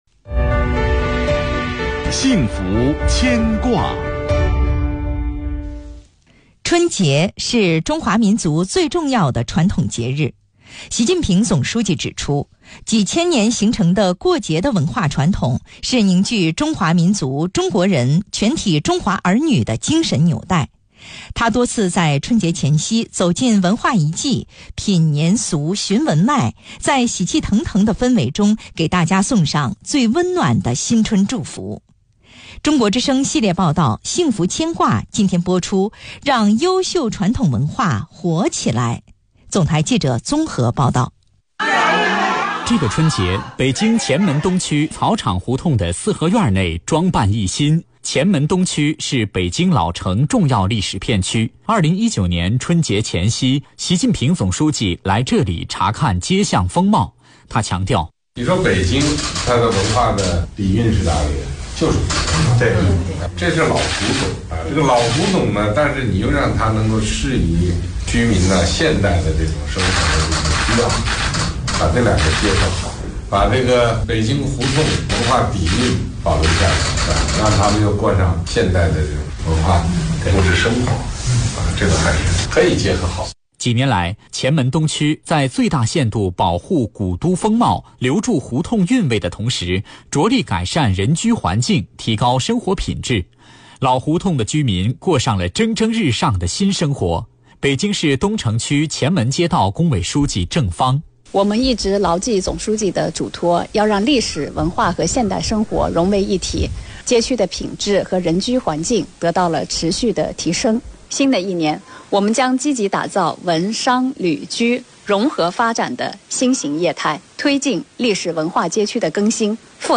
中國之聲系列報道《幸福牽掛》4日推出《讓優(yōu)秀傳統文化“活起來(lái)”》。